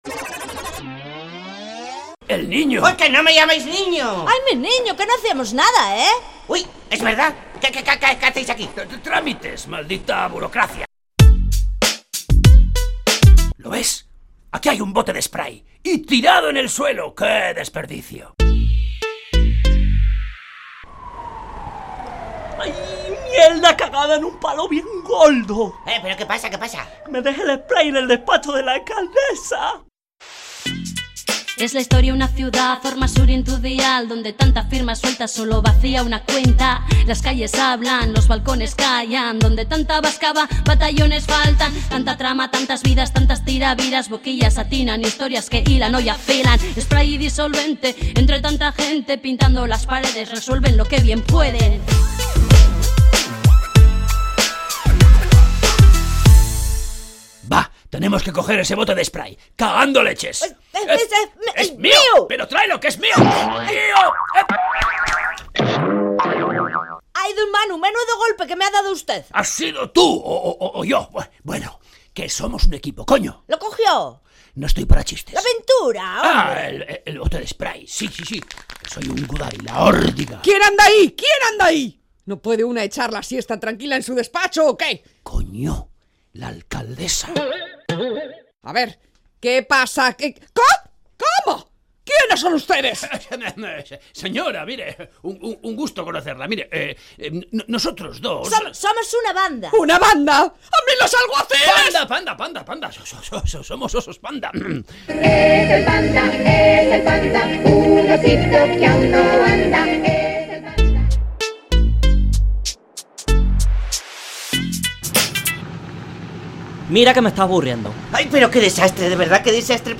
Undécima entrega de la Radio-Ficción “Spray & Disolvente”